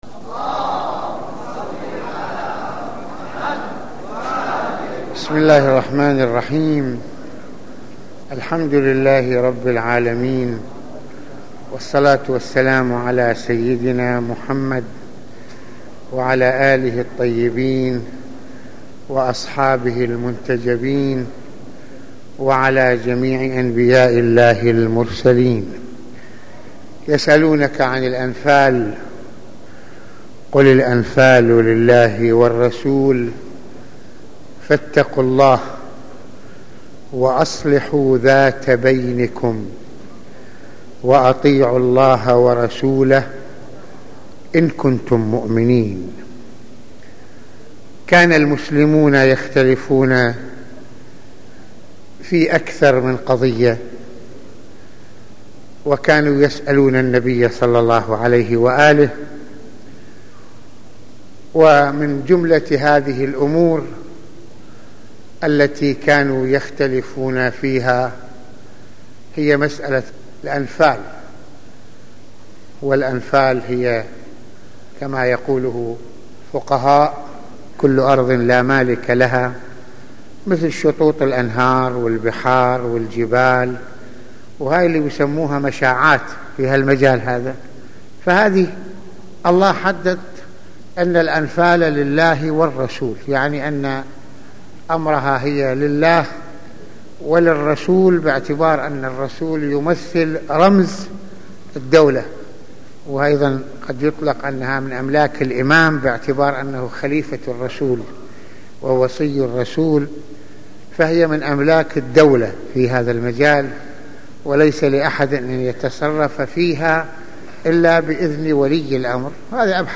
- المناسبة : موعظة ليلة الجمعة المكان : مسجد الإمامين الحسنين (ع) المدة : 33د | 22ث المواضيع : مسؤولية المؤمنين اصلاح ذات البين - التفكر بعظمة خلق الله تعالى - التربية الدينية ودورها في معرفة الله تعالى .